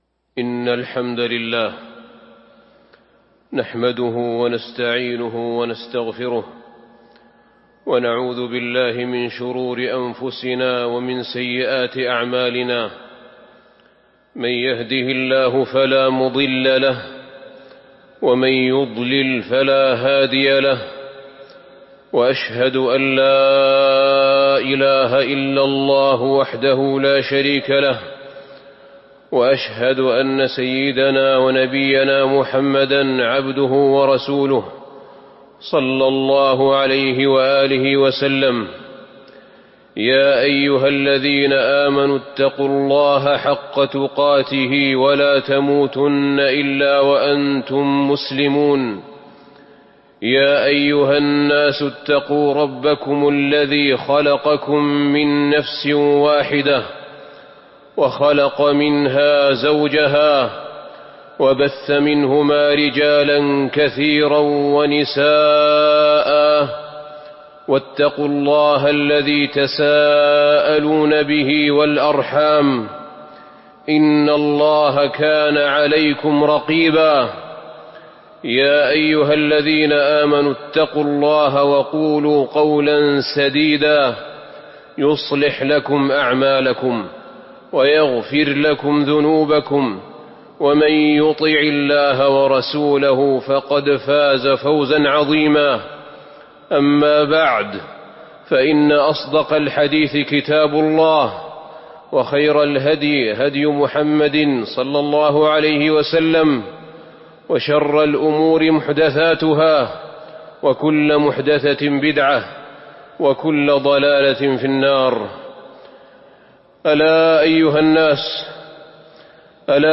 تاريخ النشر ٢٣ شوال ١٤٤٢ هـ المكان: المسجد النبوي الشيخ: فضيلة الشيخ أحمد بن طالب بن حميد فضيلة الشيخ أحمد بن طالب بن حميد شذرات نبوية